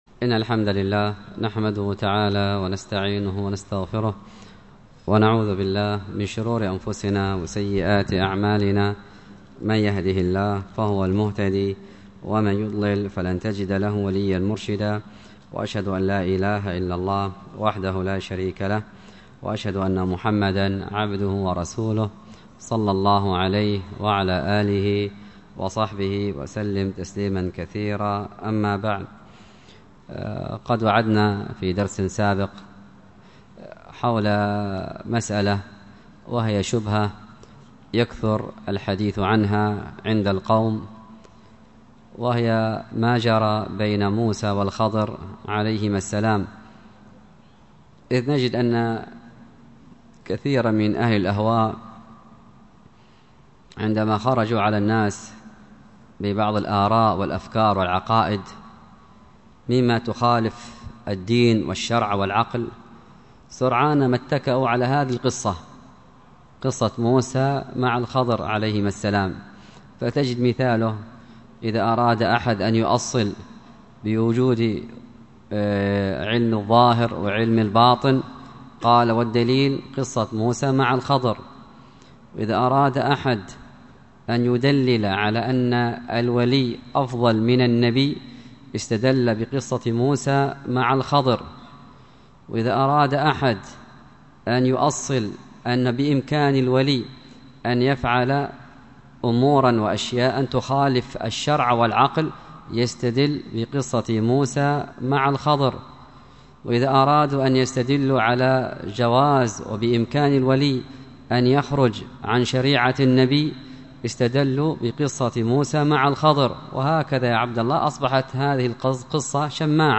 الدرس في شرح اللآلئ البهية 45، الدرس الخامس والأربعون :فيه : (والحاصل أن الواجب على العامي مثلي ...